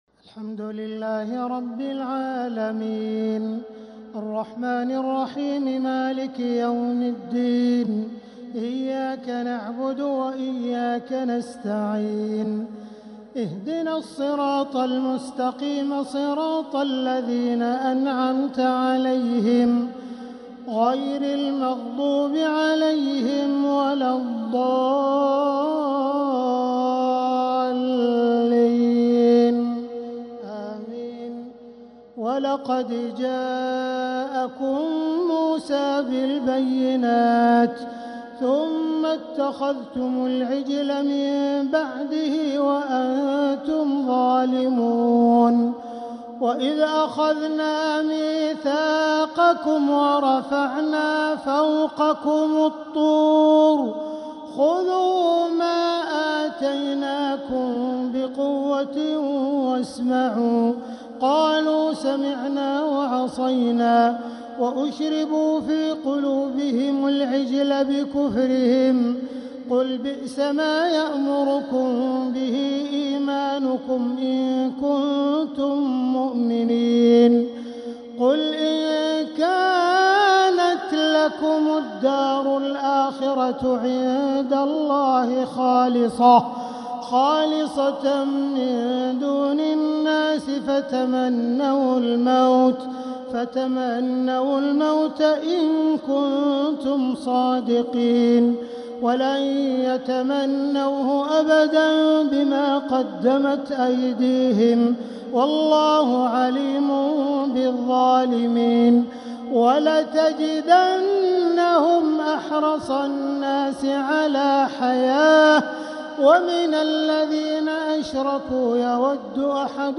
تراويح ليلة 1 رمضان 1446هـ من سورة البقرة {92-105} Taraweeh 1st night Ramadan 1446H > تراويح الحرم المكي عام 1446 🕋 > التراويح - تلاوات الحرمين